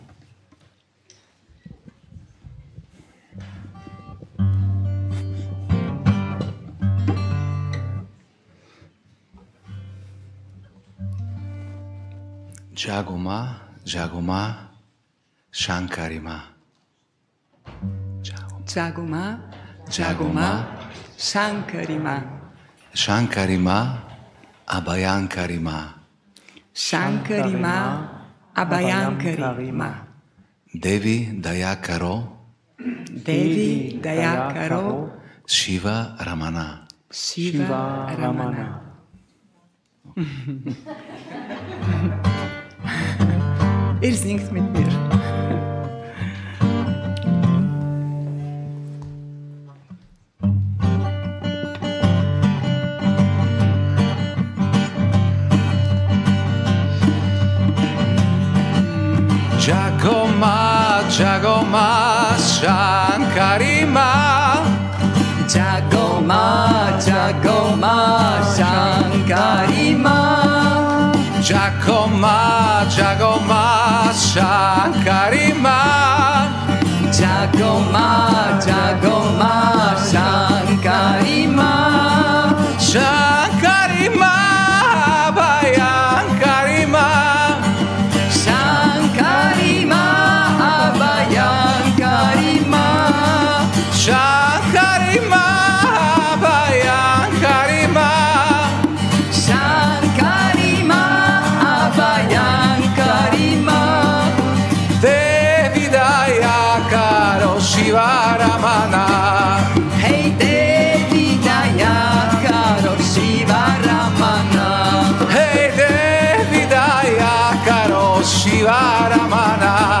Beschreibung vor 2 Wochen Jago Ma Jago Ma Dieser Kirtan vereint das kraftvolle Mantra „Jago Ma Jago Ma …
Inhalt & Aufbau der Aufnahme Der Kirtan nutzt eine einfache, aber kraftvolle Wiederholung: Das Wort „Jago“ (wache auf), verbunden mit „Ma“ (Mutter), ruft im kollektiven Singen die göttliche Mutter an.
Der Klang gestaltet sich zyklisch — von ruhiger, meditativer Stimmung bis hin zu aufbauender, gemeinschaftlicher Energie.